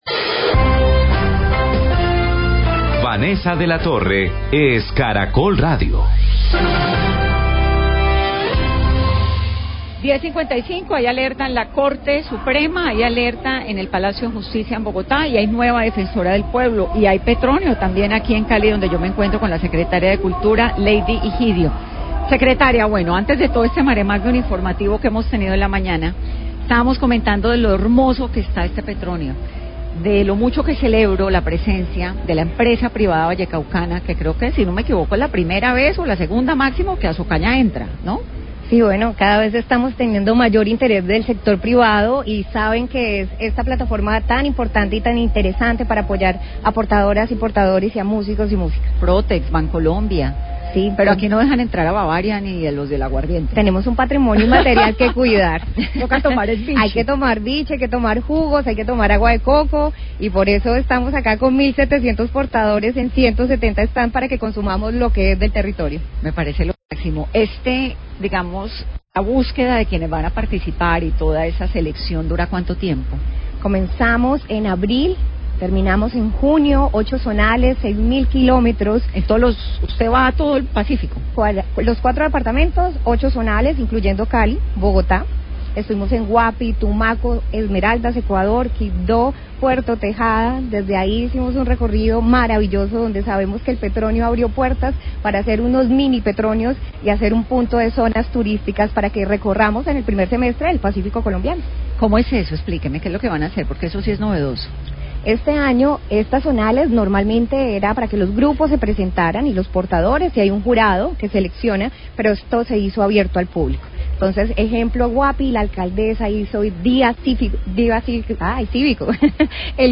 Entrevista con la Secretaria de Cultura de Cali, Leydi Higidio, quien hace un recuento del proceso de selección de las agrupaciones de música del pacífico quienes participarán en las finales del Festival Petronio Álvarez.